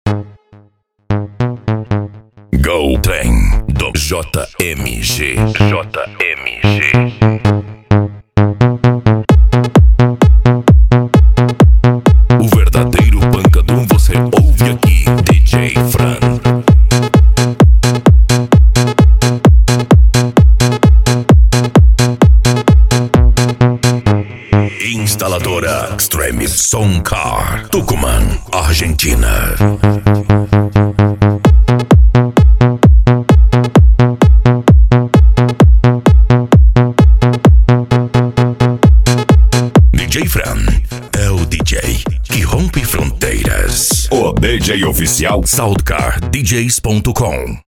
Bass
Cumbia
PANCADÃO
Remix